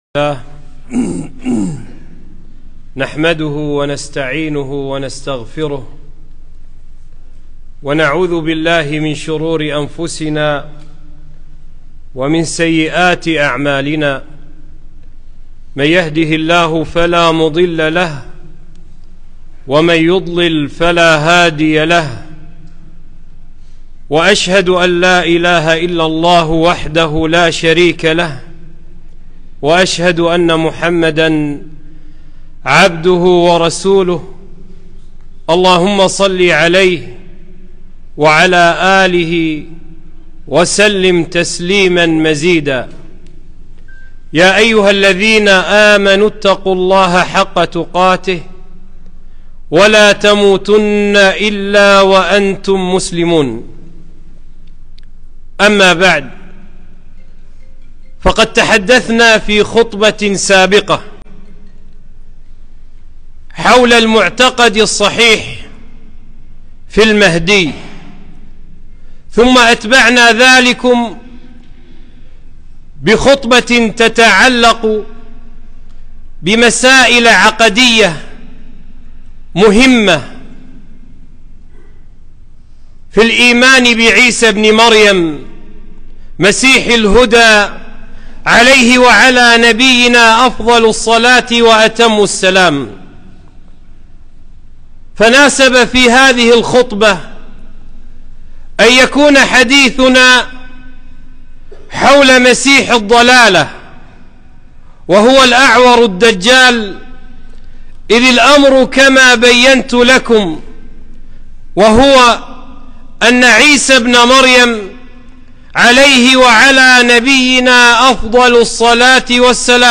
خطبة - إيجاز المقال في معتقد أهل السنة في المسيح الدجال